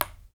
Index of /90_sSampleCDs/Roland L-CD701/PRC_FX Perc 1/PRC_Ping Pong